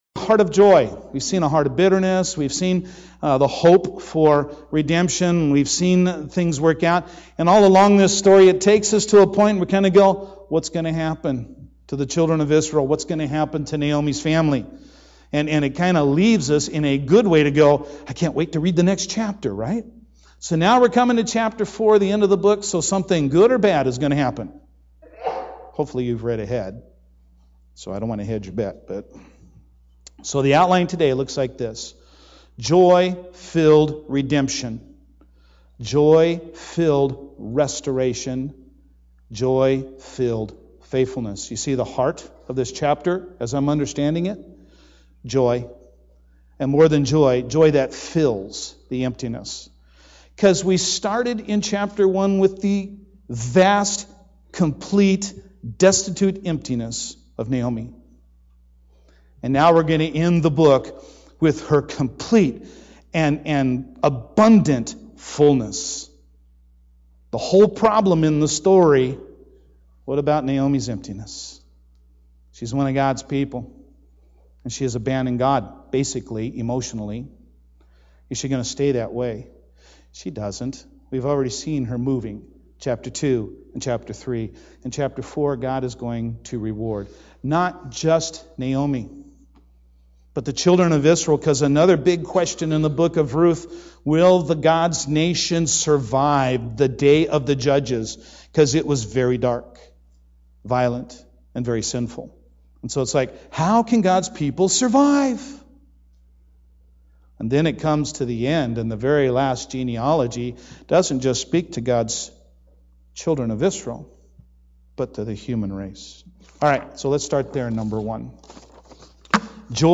Sermons - North Mason Bible Church - Page 42